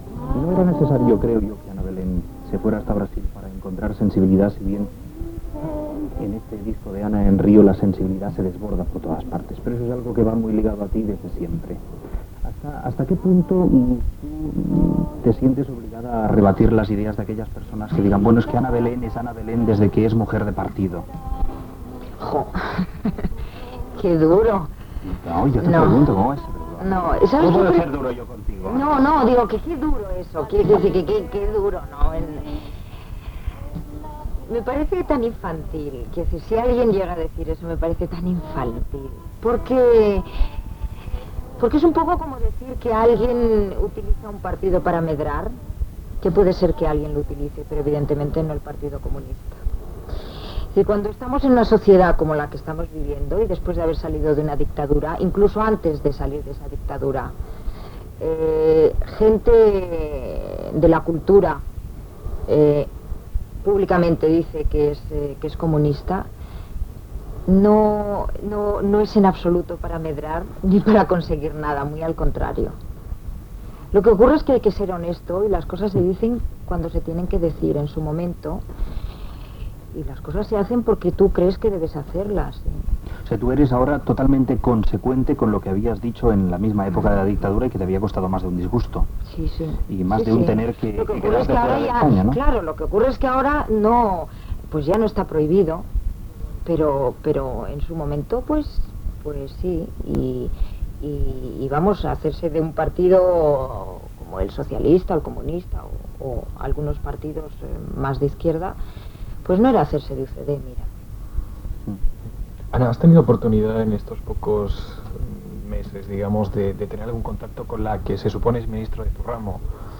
Entrevista a la cantant i actriu Anna Belén sobre la seva feina, la seva militància política i la política cultural
Entreteniment